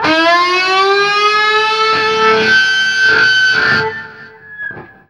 DIVEBOMB 7-L.wav